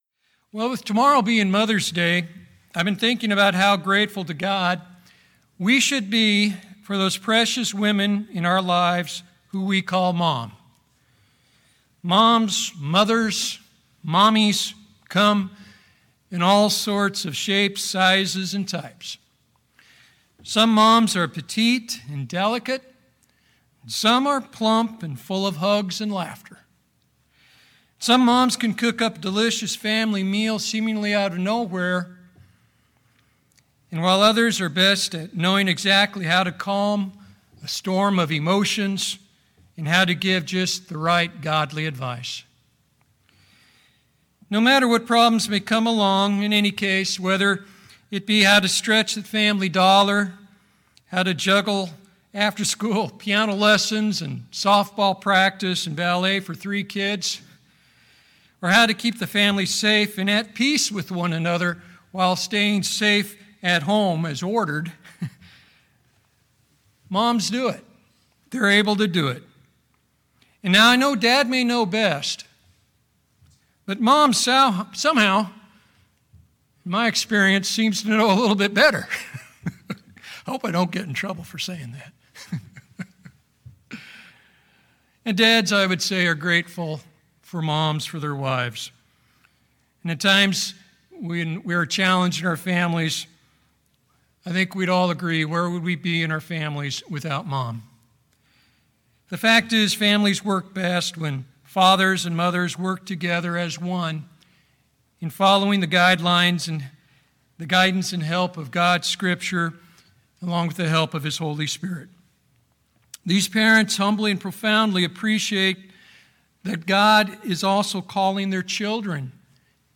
In this split sermon, we will review four biblical keys for godly parenting. And since tomorrow is Mother's Day, we will give special consideration to how mothers apply and can apply these keys with their children.